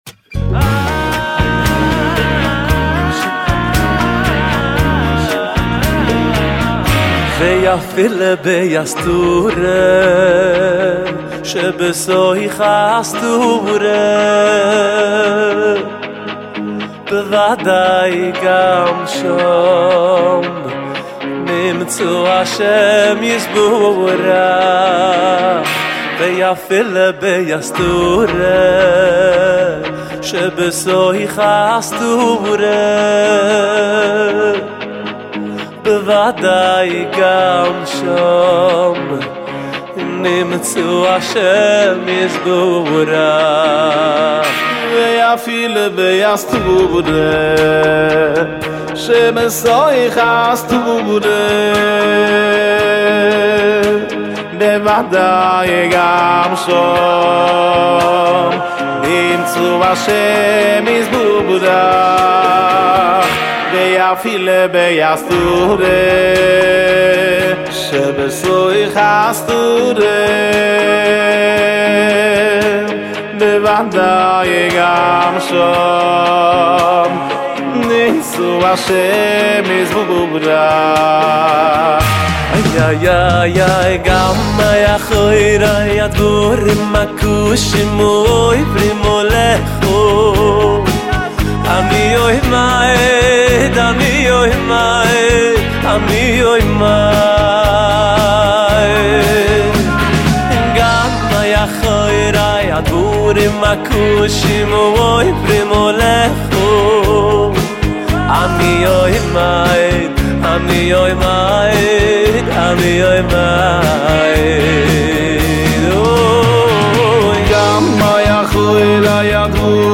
דואט